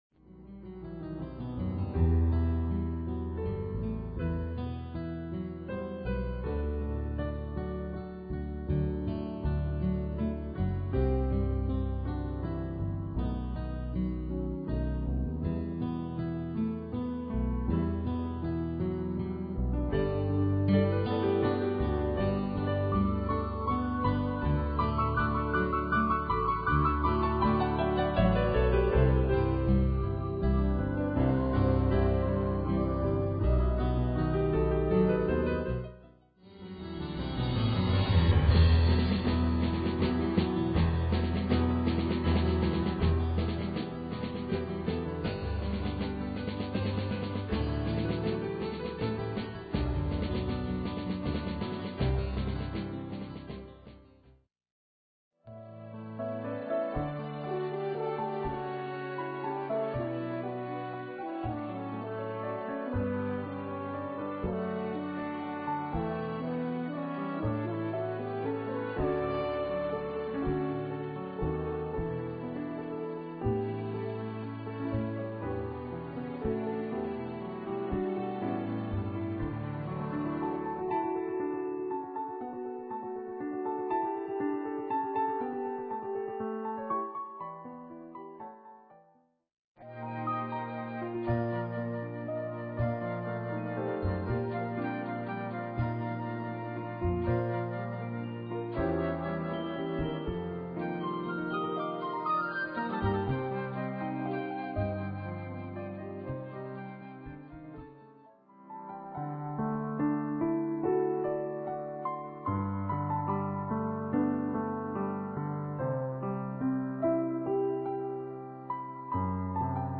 Solo piano with light orchestration.
Original and familiar melodies in a contemporary style
Gentle and calming.